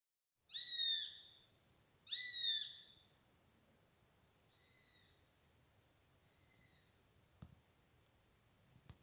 Zvirbuļvanags, Accipiter nisus
StatussTikko šķīlušies mazuļi vai vecāki ar mazuļiem (RM)